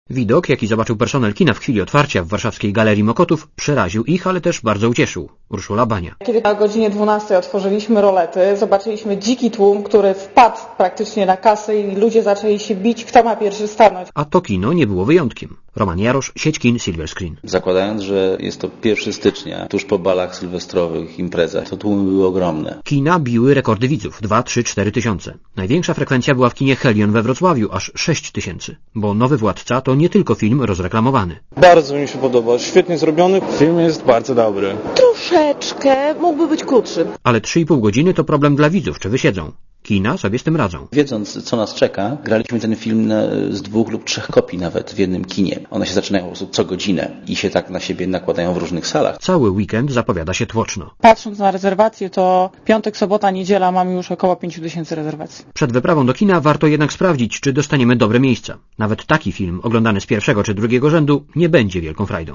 Komentarz audio (244Kb)